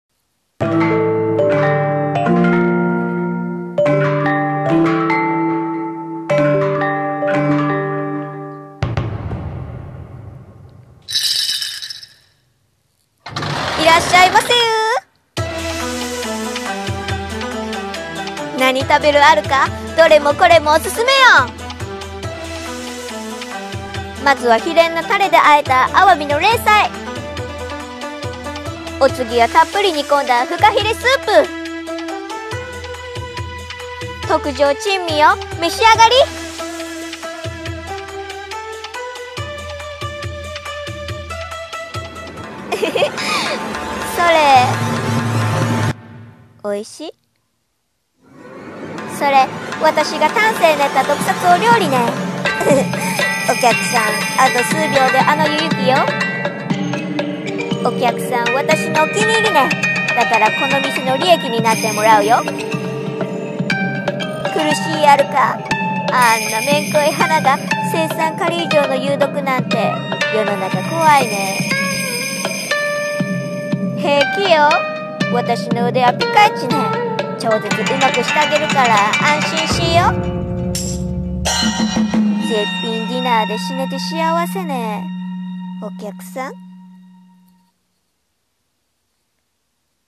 】【声劇】珍味の鈴蘭中華店 亭主